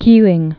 (kēlĭng)